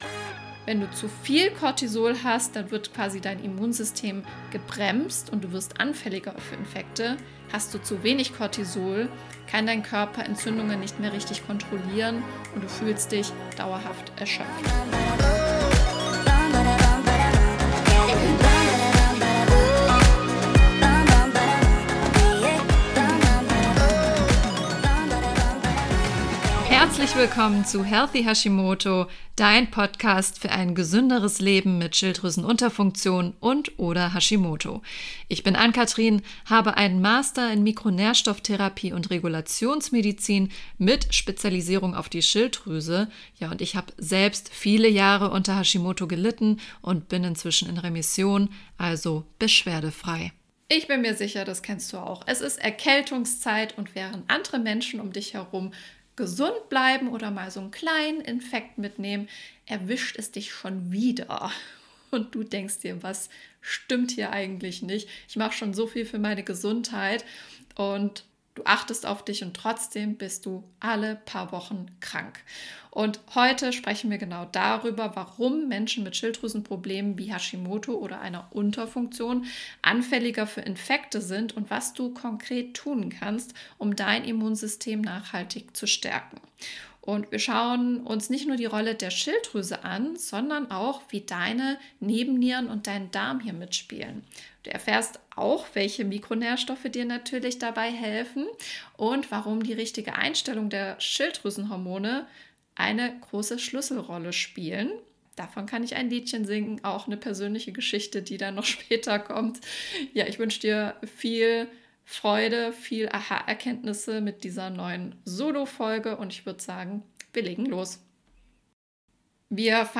In dieser Solo-Folge erfährst du, warum Menschen mit Schilddrüsenproblemen wie Hashimoto oder einer Schilddrüsenunterfunktion anfälliger für Infektionen sind und was du tun kannst, um dein Immunsystem nachhaltig zu stärken.